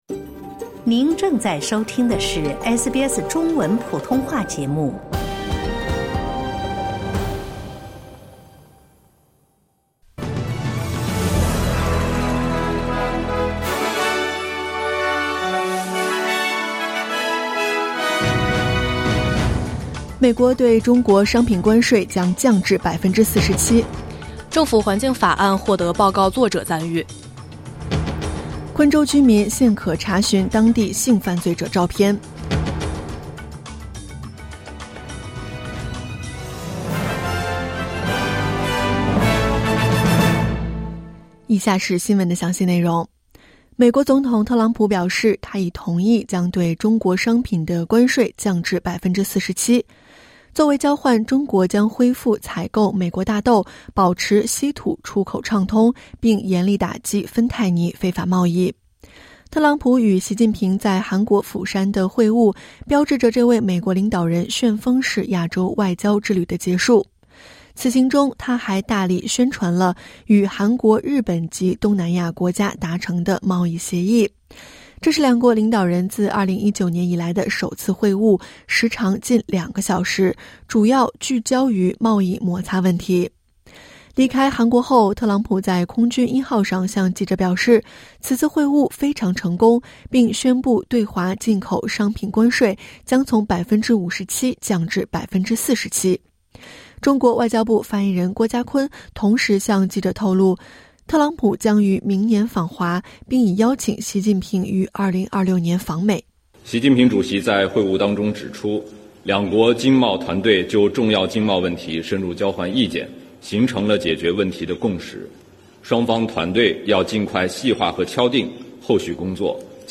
新闻快报